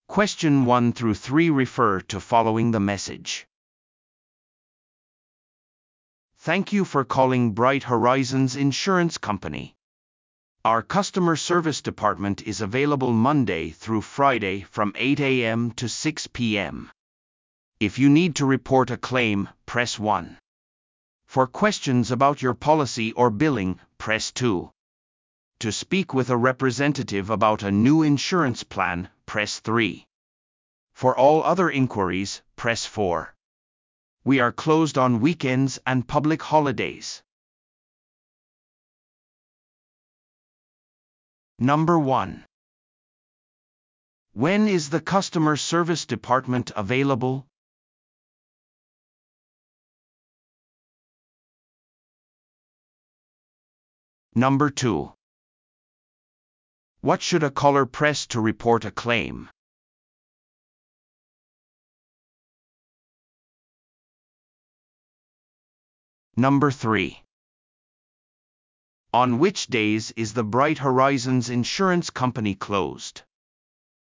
PART４は一人語りの英語音声が流れ、それを聞き取り問題用紙に書かれている設問に回答する形式のリスニング問題。